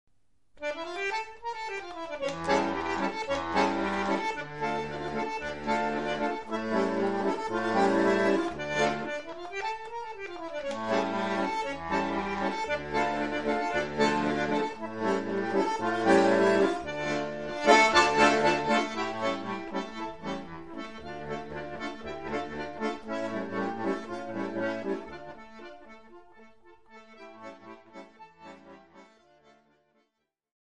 Bandoneon